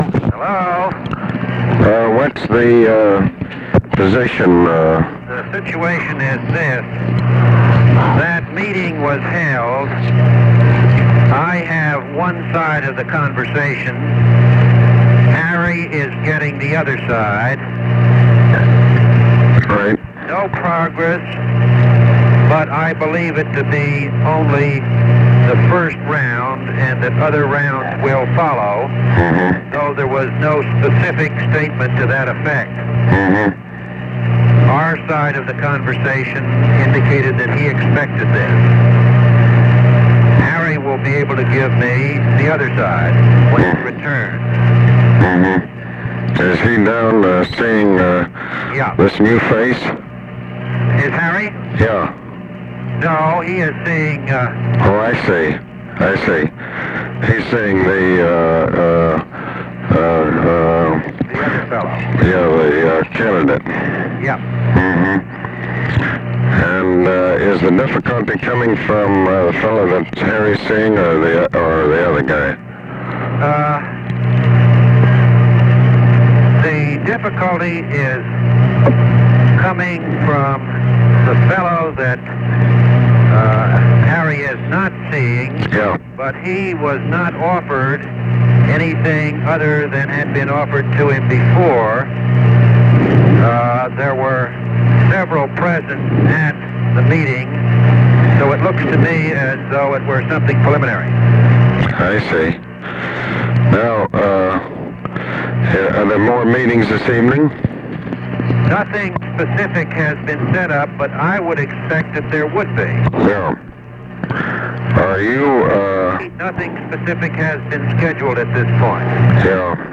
Conversation with ABE FORTAS
Secret White House Tapes